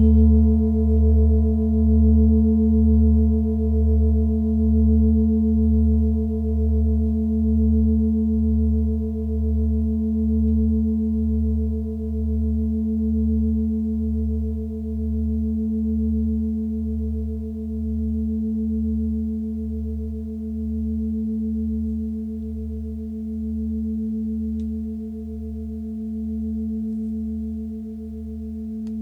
Die Besonderheit dieser Klangschale liegt in ihrem starken Boden und ihrer relativ dazu dünnen Wandung. Dadurch bedarf es nur sanfter Schläge, um die Schale zum starken Schwingen anzuregen.
Hörprobe der Klangschale
In unserer Tonleiter liegt dieser Ton nahe beim "D".
klangschale-nepal-44.wav